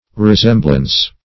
Resemblance \Re*sem"blance\ (-blans), n. [Cf. F. ressemblance.